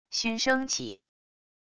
埙声起wav音频